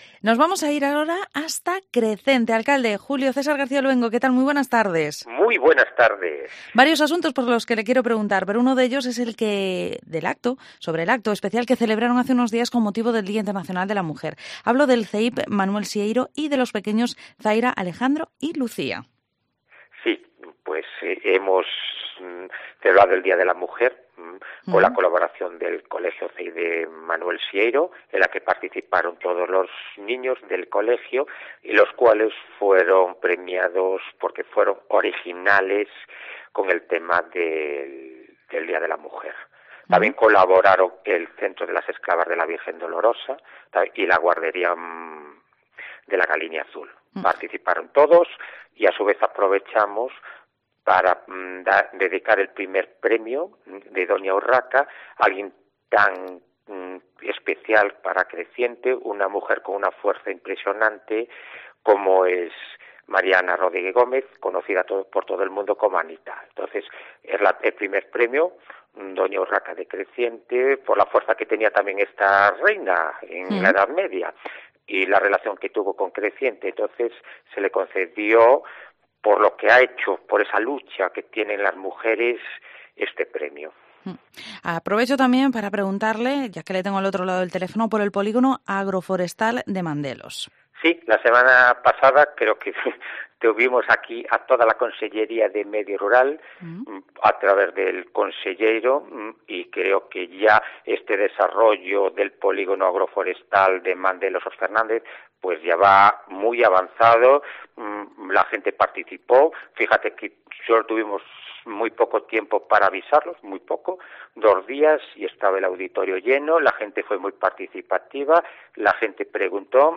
AUDIO: Entrevista al Alcalde de Crecente, Julio César García Luengo.